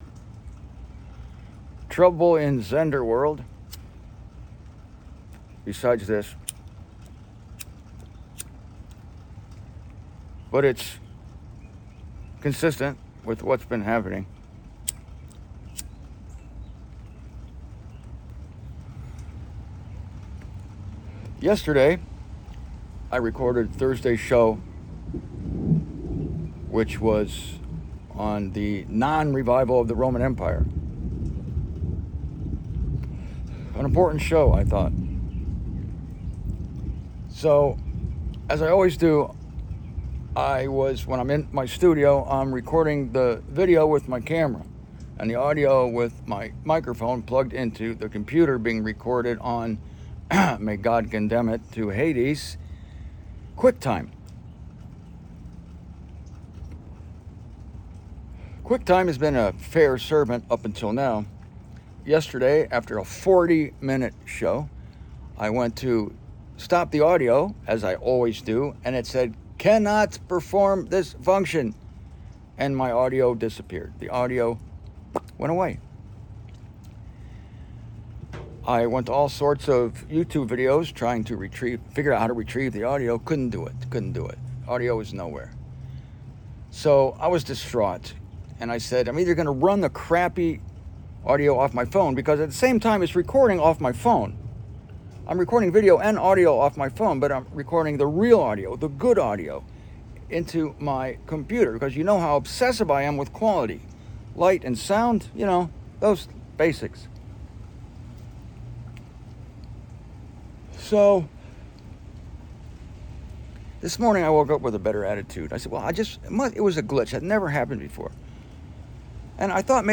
First of all, I am frustrated as can be at the failure of my audio recording software to save the audio of this presentation, forcing me to use the back-up audio from my phone (with which I record the show's video). As everyone known, the microphones on phones, even iPhones, is sub-par, especially when recorded at a distance of five feet.
Hell no. You could leave it at that and forego the video, but hell yes, the message is worthy and—for all my bitching—the audio is actually passable.